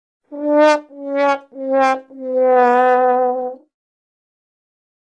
Download Free Womp Womp Sound Effects
Womp Womp